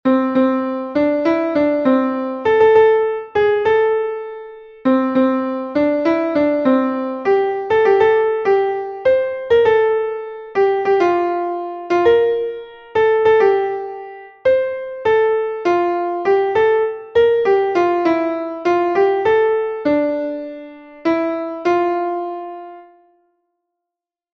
Not mucky and sung with a Somerset accent.